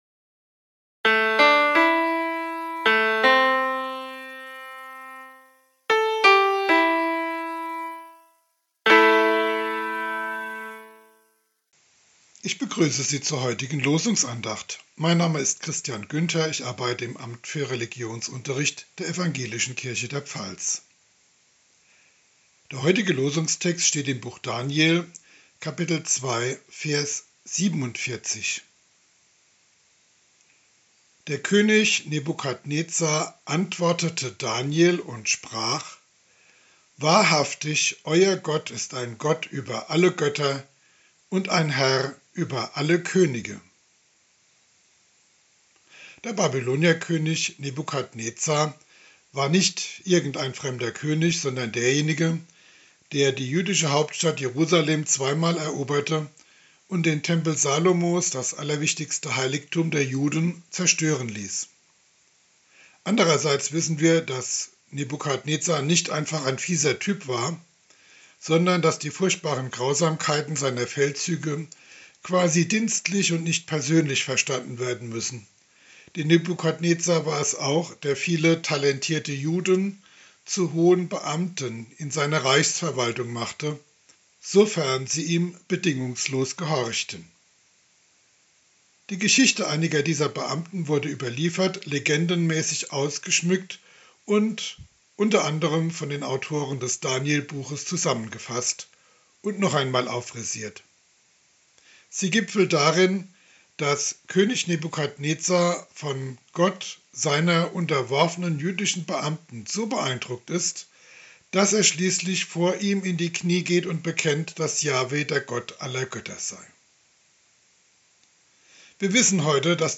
Losungsandacht für Mittwoch, 01.03.2023